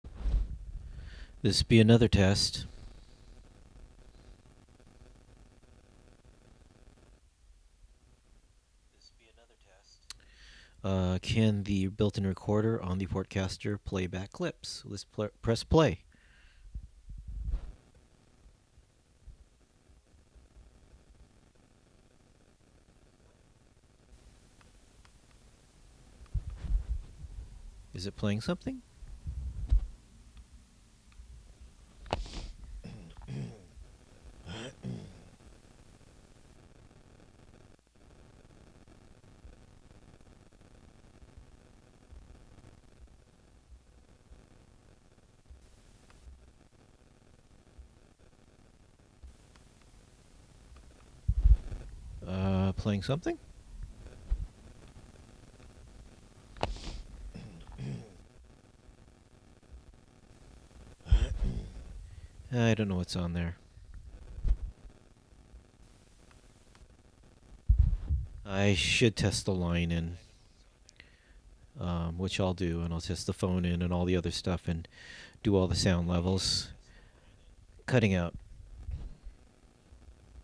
Live from the Thingularity Studios